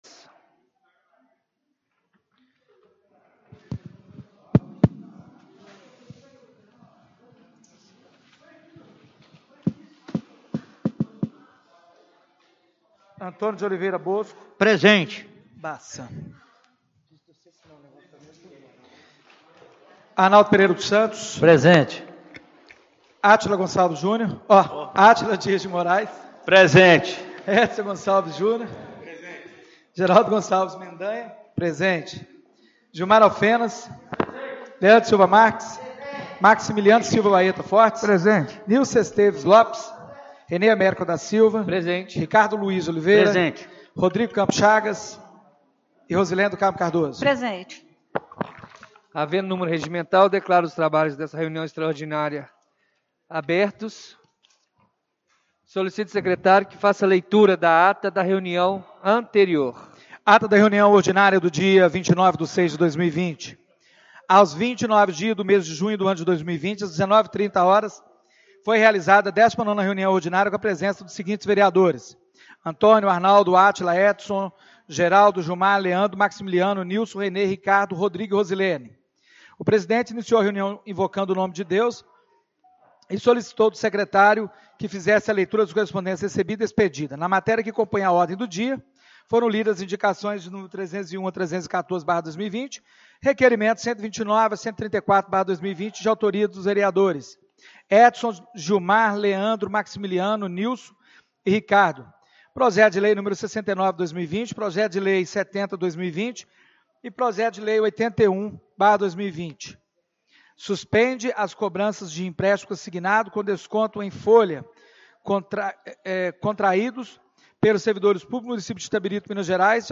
Reunião Extraordinária do dia 01/07/2020